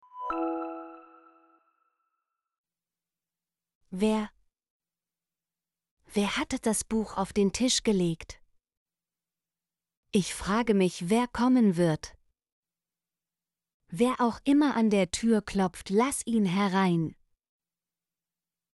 wer - Example Sentences & Pronunciation, German Frequency List